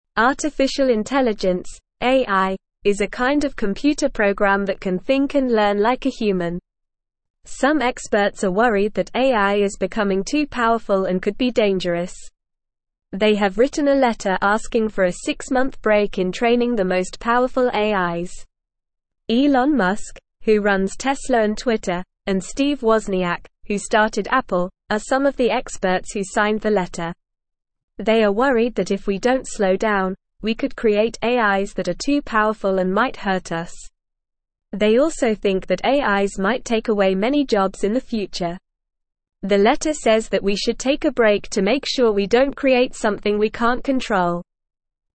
Normal
English-Newsroom-Beginner-NORMAL-Reading-Experts-Ask-for-Break-in-AI-Training.mp3